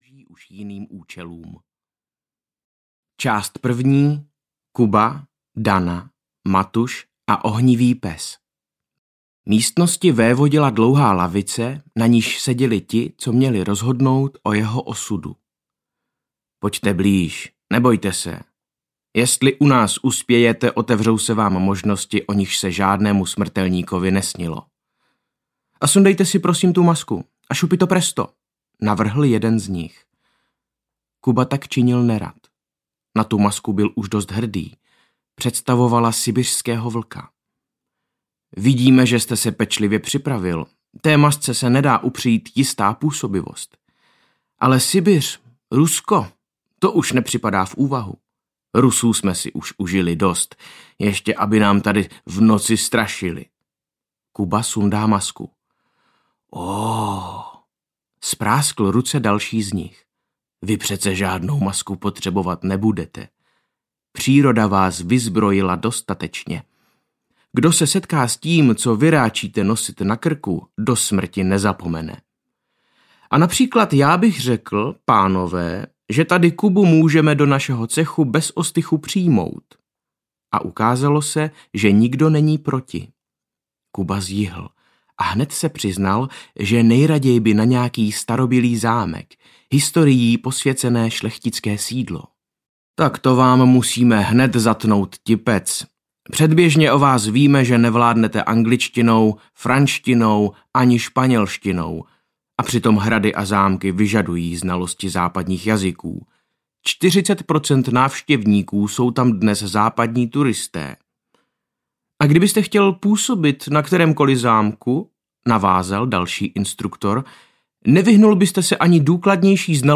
Strach a bída strašidel audiokniha
Ukázka z knihy